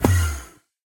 portal.ogg